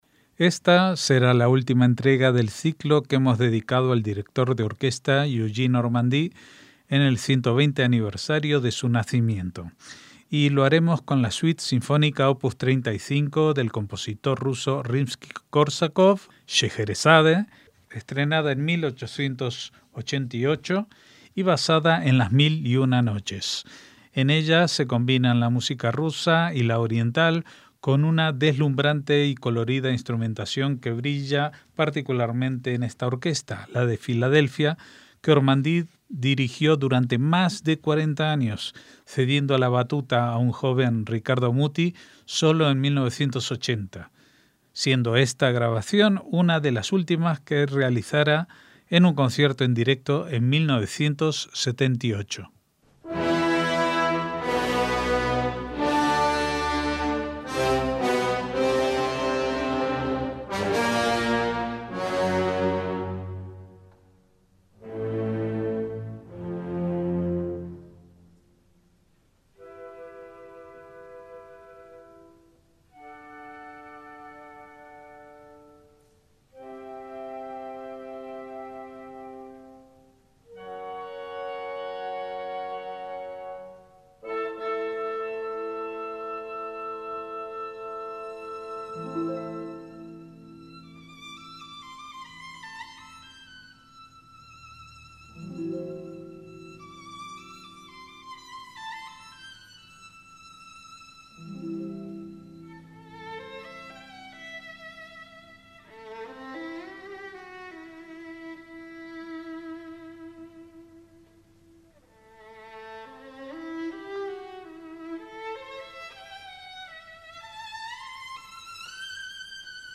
MÚSICA CLÁSICA
en un concierto en directo en 1978